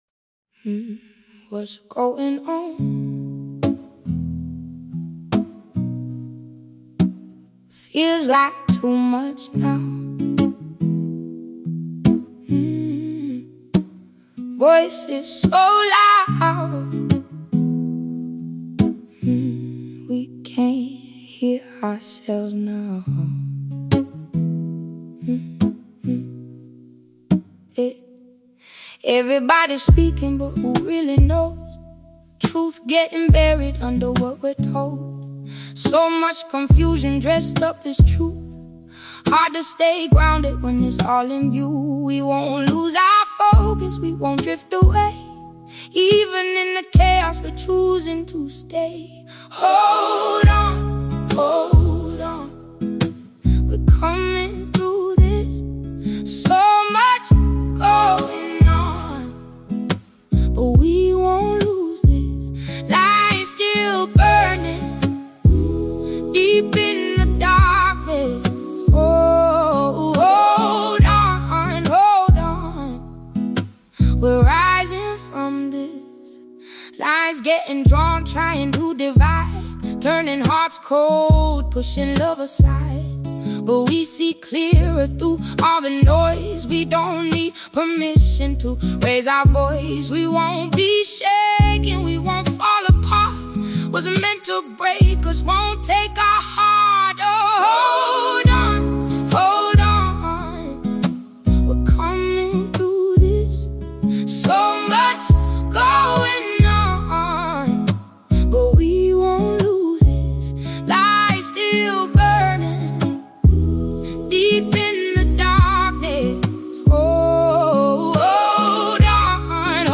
There is a comforting presence in how this song unfolds.
As it continues, the tone becomes more personal.